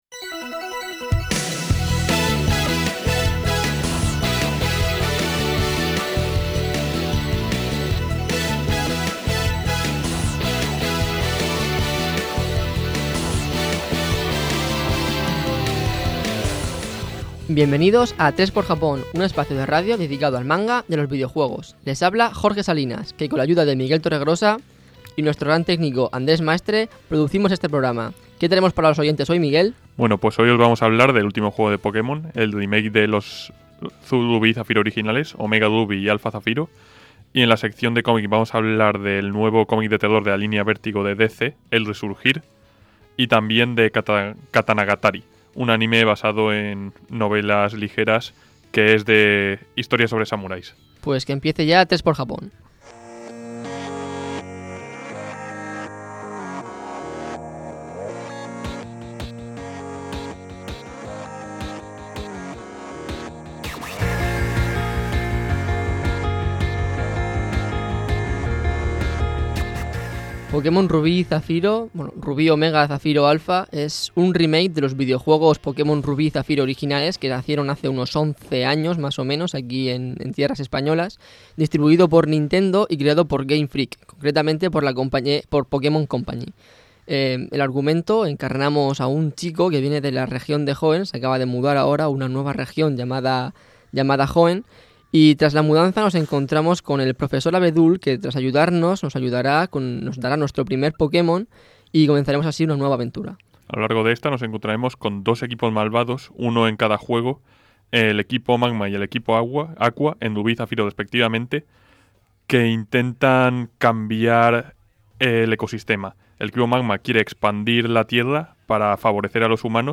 En él se pueden escuchar entrevistas a aficionados y expertos.